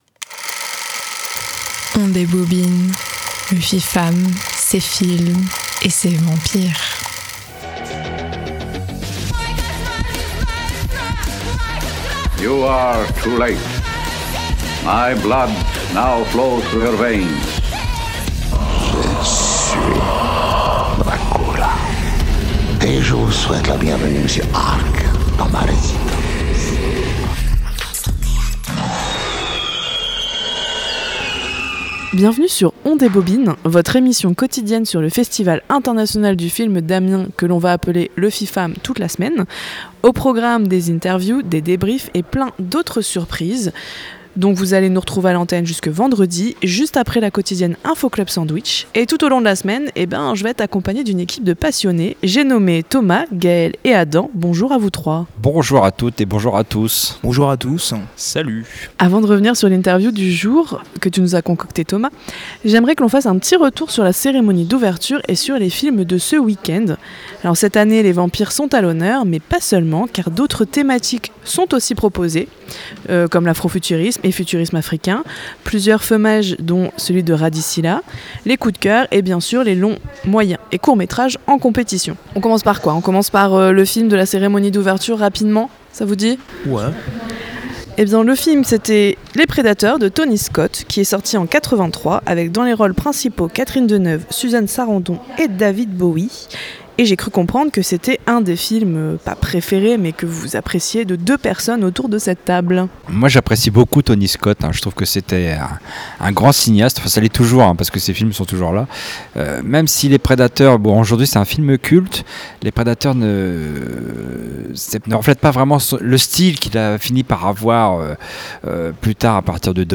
Bienvenue dans On débobine, l’émission spéciale autour du Festival International du Film d’Amiens (le FIFAM).